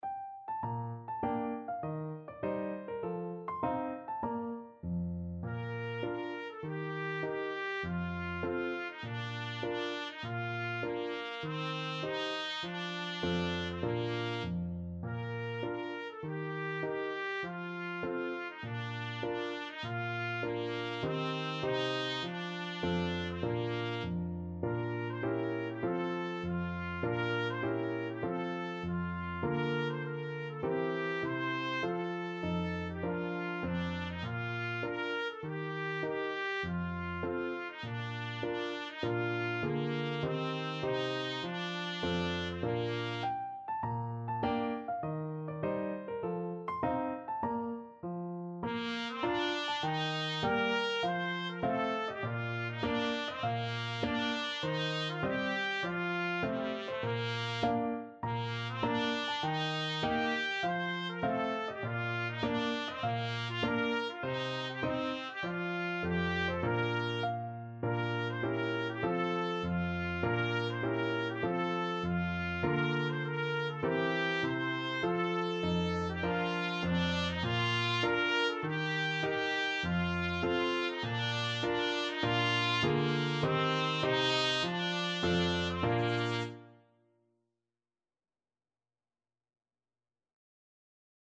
Trumpet
Traditional Music of unknown author.
Bb major (Sounding Pitch) C major (Trumpet in Bb) (View more Bb major Music for Trumpet )
Moderato
4/4 (View more 4/4 Music)